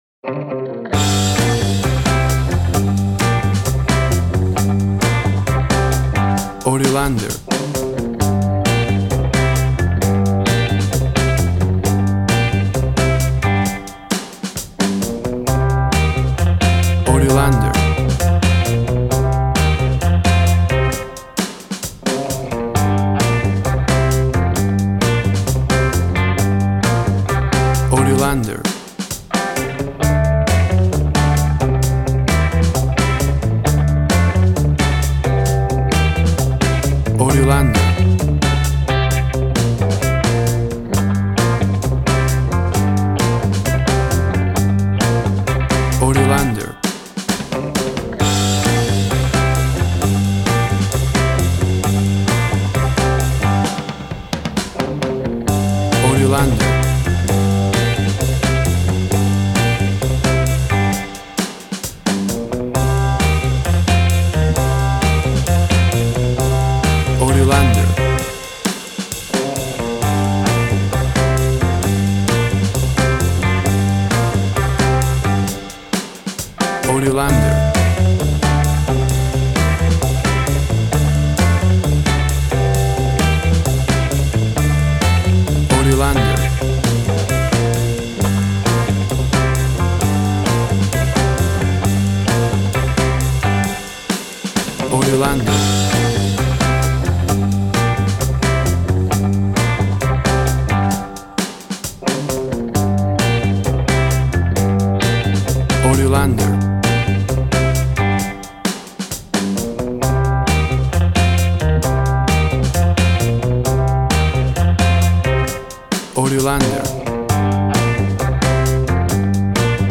Rock pop of the 60´s vintage.
Tempo (BPM) 135